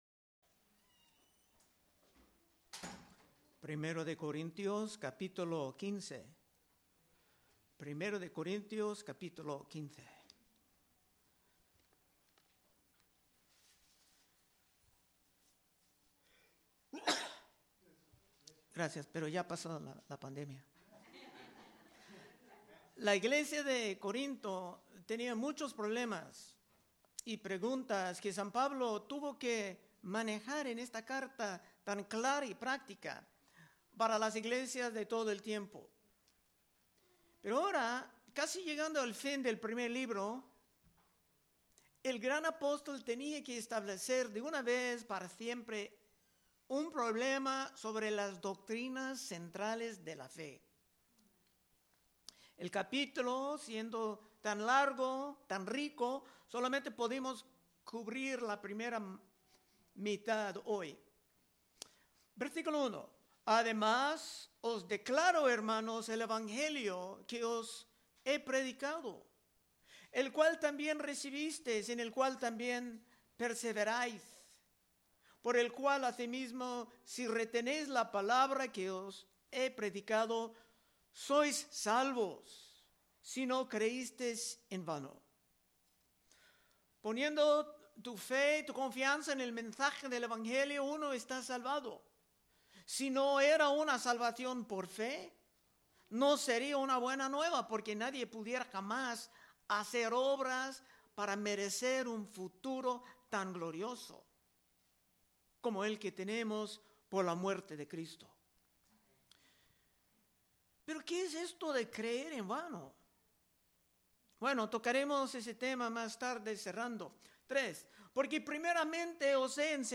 Predicaciones De Exposición Libro De 1 Corintios